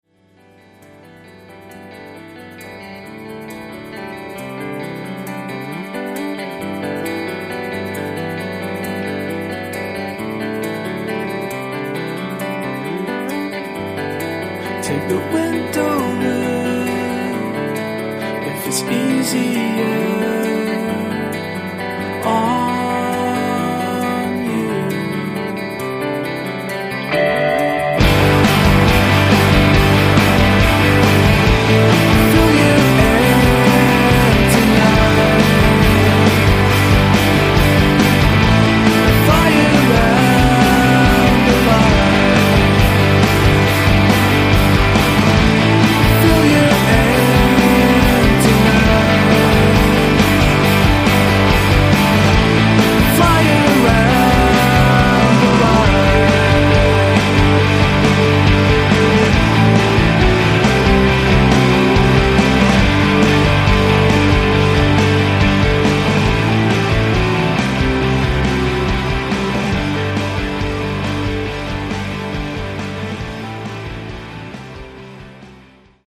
Maybe these songs are a bit too easy on the ears.